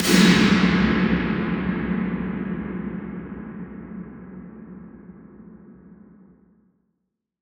Big Drum Hit 15.wav